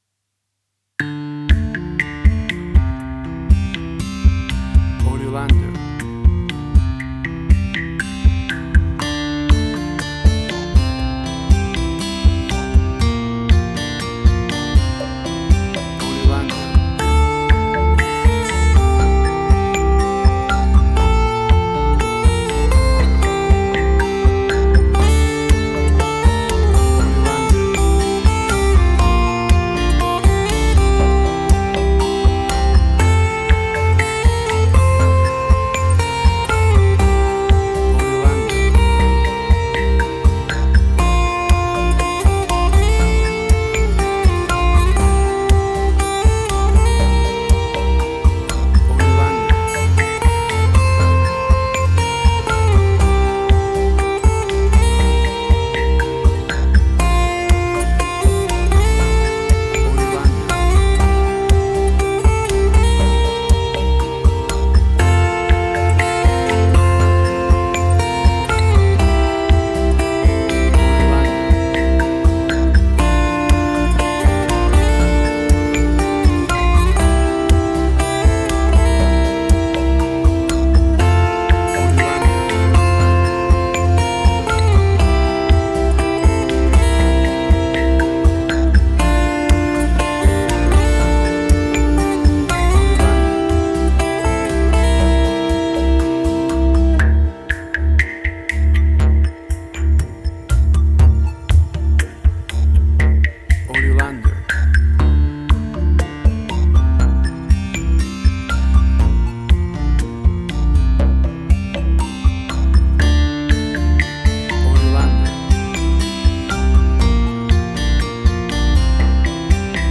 Tempo (BPM): 60